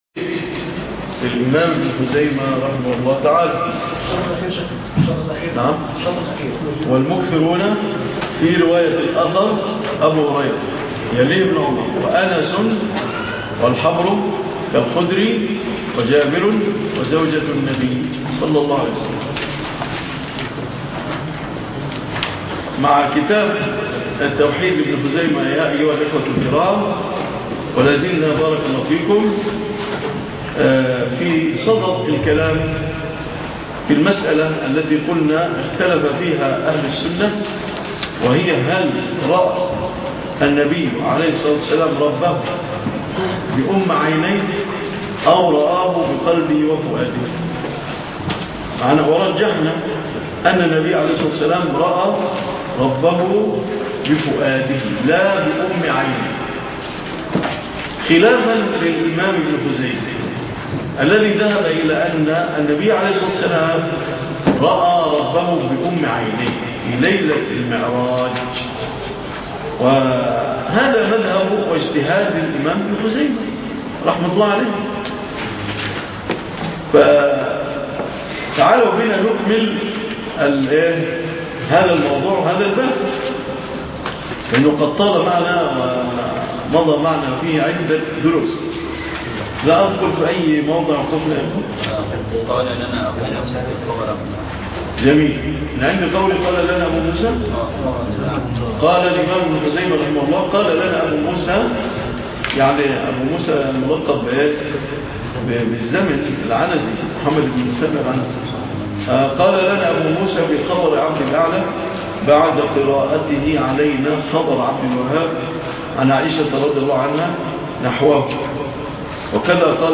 الدرس 29 ( شرح كتاب التوحيد لابن خزيمة